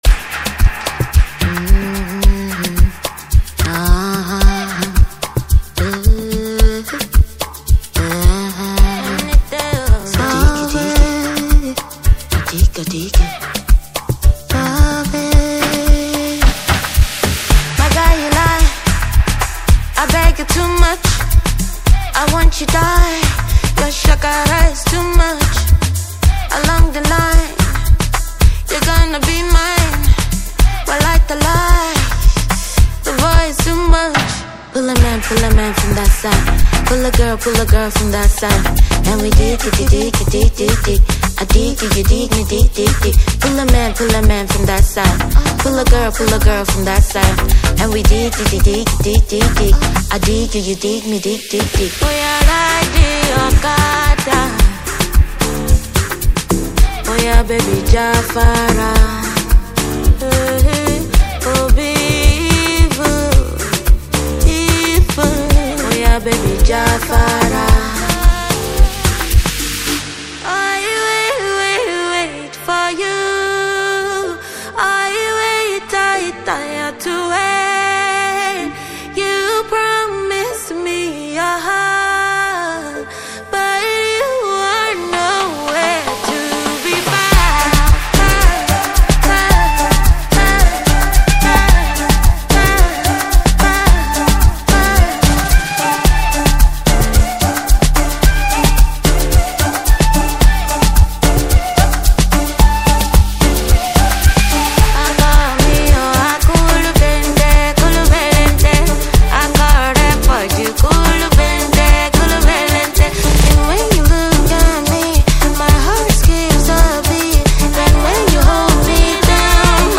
Nigerian Afro house queen